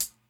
Closed Hats
pcp_hihat03.wav